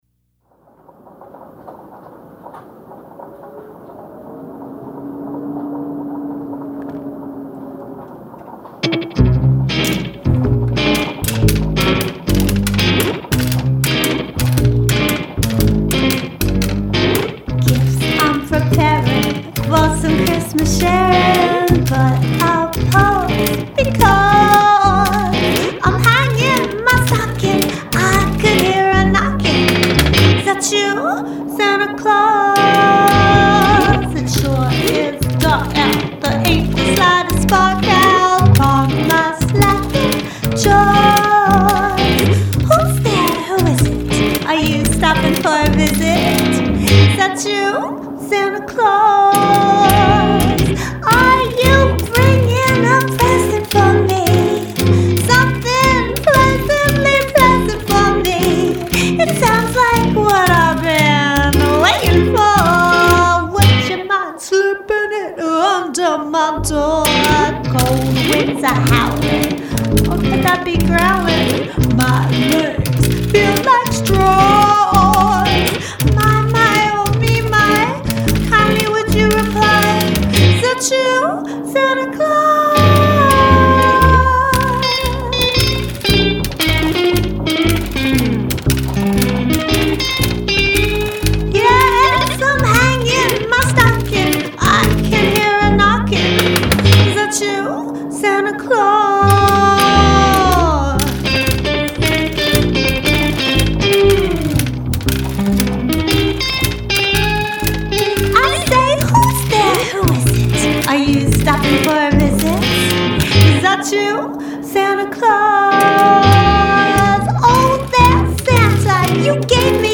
glistens with a sassy femme perspective
lead vocalist and washboard percussionist
jubilant cigar box guitar